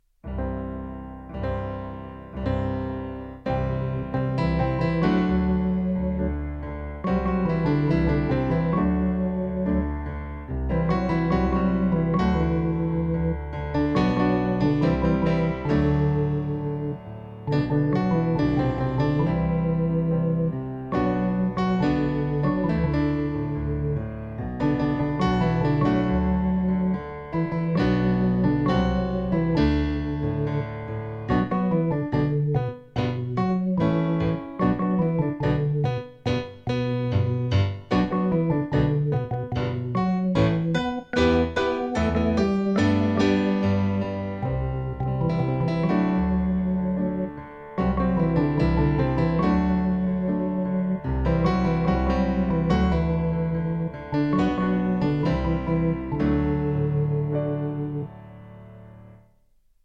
Just like in class, every recording will start with three piano chords to get ready before the melody starts and you can sing along (or simply follow along reading the score).  I used a different “instrument” from my keyboard’s sound library for each melody.